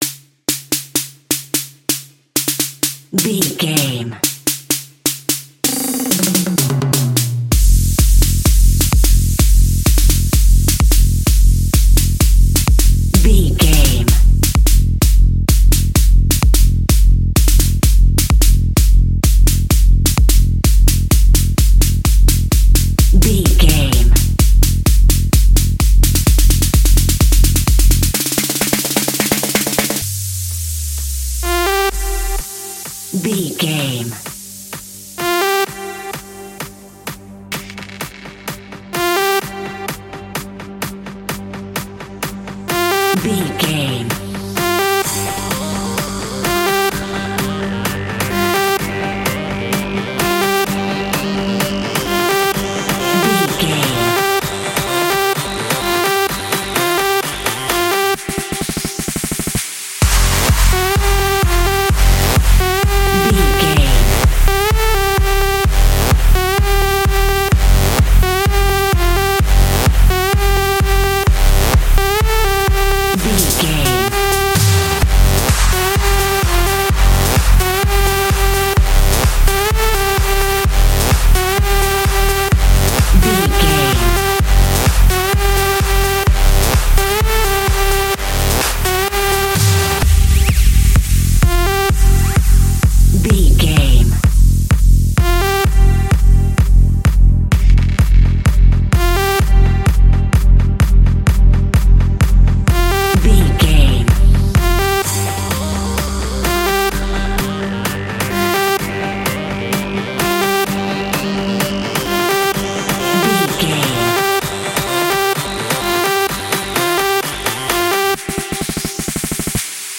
Aeolian/Minor
frantic
energetic
uplifting
hypnotic
drum machine
synthesiser
acid house
fast
synth leads
synth bass